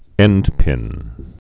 (ĕndpin)